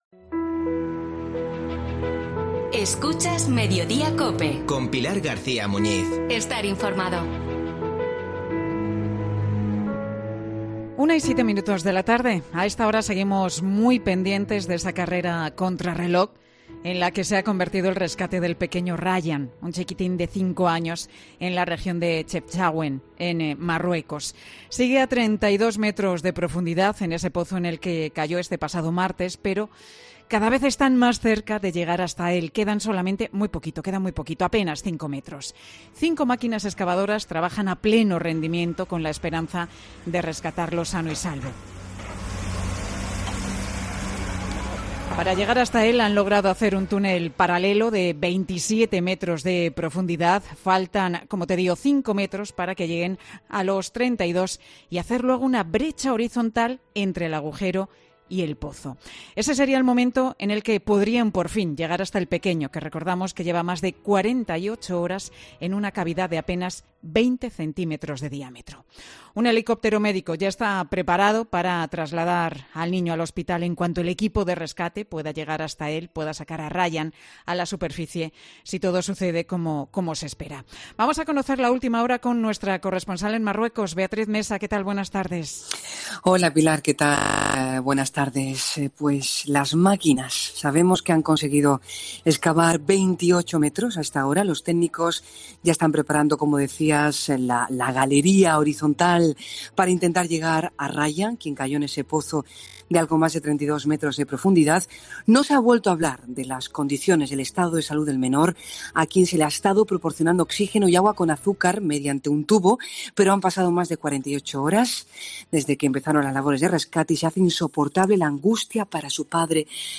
Uno de los bomberos que ayudó en el rescate de Julen: "Trabajamos a contrarreloj y con mucha emoción"